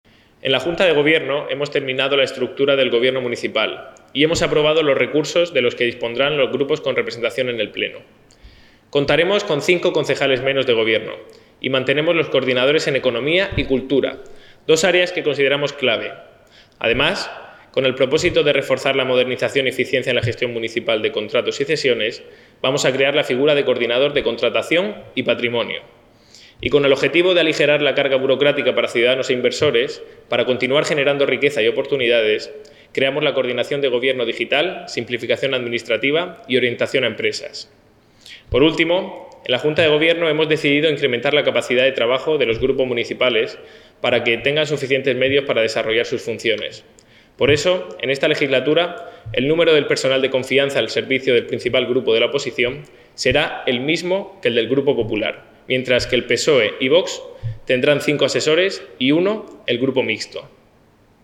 Enlace a Declaraciones del concejal Ignacio Jáudenes.